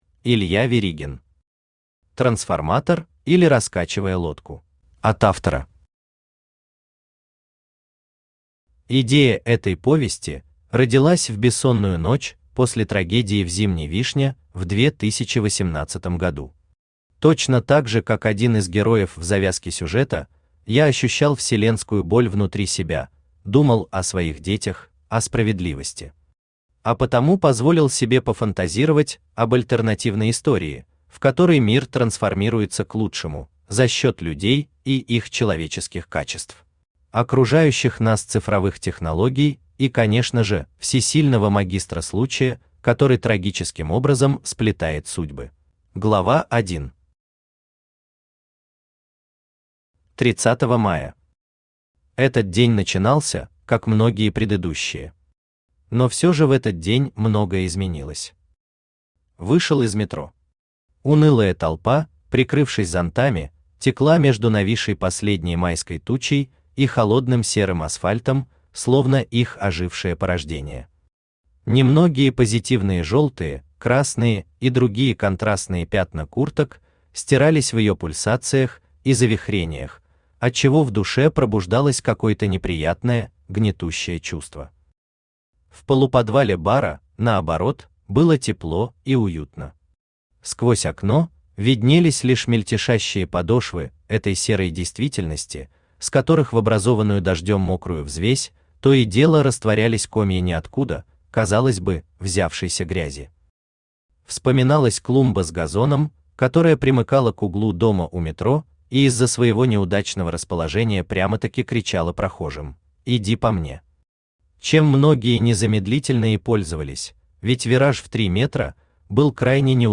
Aудиокнига Трансформатор, или Раскачивая лодку Автор Илья Веригин Читает аудиокнигу Авточтец ЛитРес. Прослушать и бесплатно скачать фрагмент аудиокниги